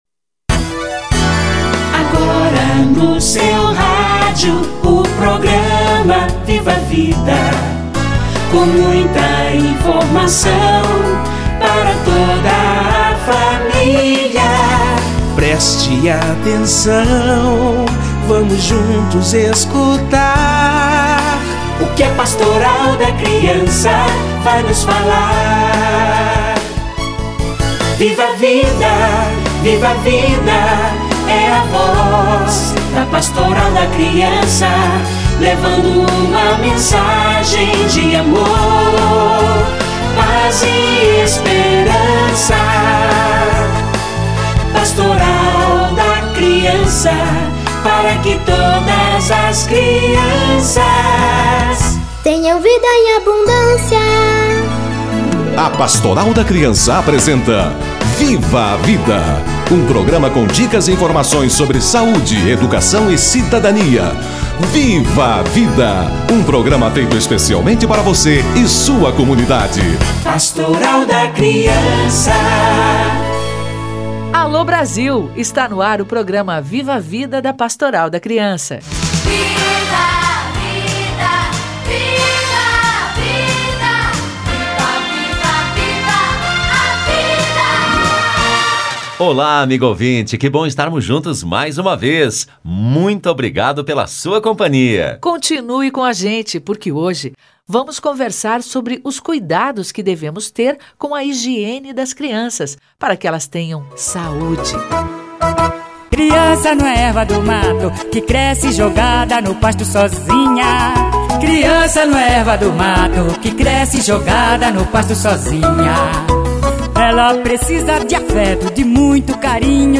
Higiene e saúde da criança - Entrevista